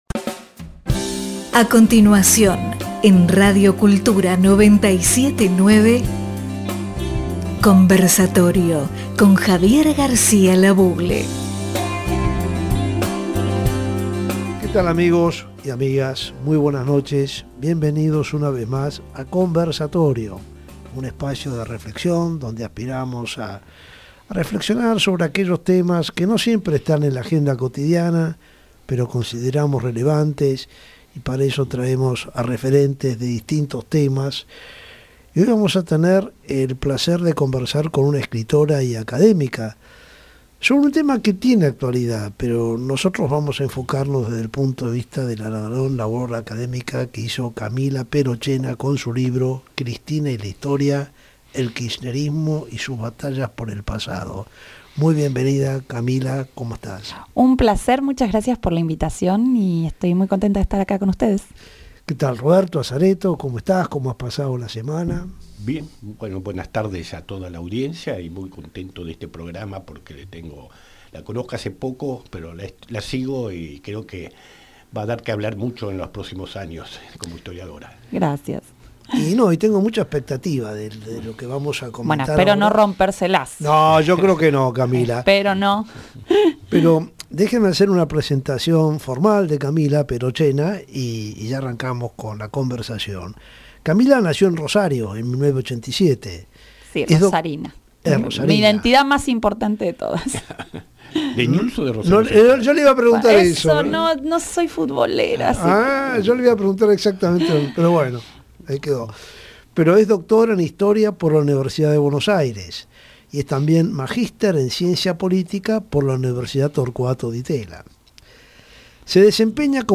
Iniciamos este ciclo radial que hemos llamado Conversatorio, porque precisamente aspiramos a compartir con la audiencia nuestras visiones y reflexiones sobre aquellos temas que consideramos relevantes, pero sentimos que muchas veces están fuera de la agenda y la discusión cotidiana, dominada abrumadoramente por la coyuntura y la anécdota diaria.